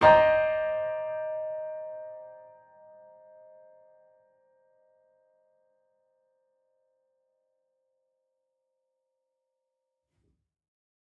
Index of /musicradar/gangster-sting-samples/Chord Hits/Piano
GS_PiChrd-Cmin6+9.wav